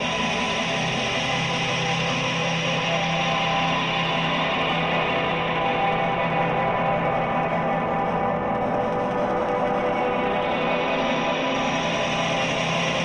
短暂而简单的旋律
Tag: 130 bpm Hip Hop Loops Piano Loops 1.24 MB wav Key : G FL Studio